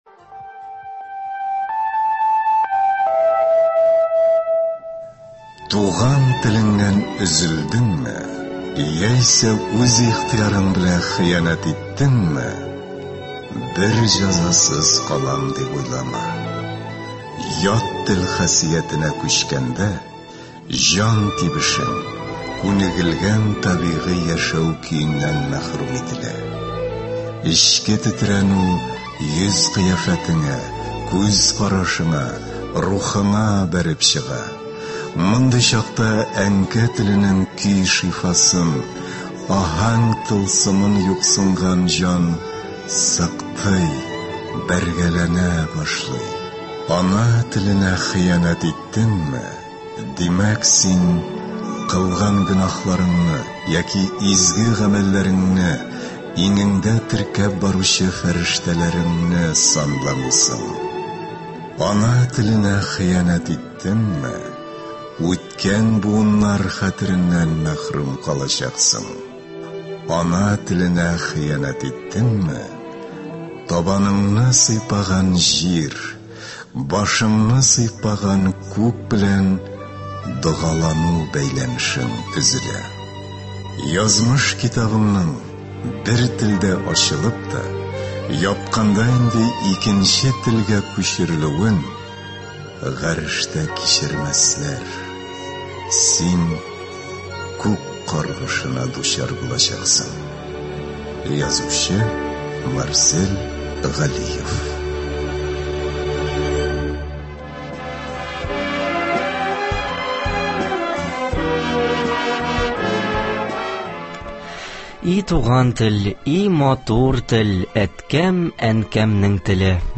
Бүгенге көндә яшьләр арасында татар телен өйрәнергә омтылучылар күпме? Бу һәм башка сорауларга җавапны туры эфирда ТР мөфтиенең мәгариф буенча урынбасары Рәфыйк Мөхәммәтшин бирә.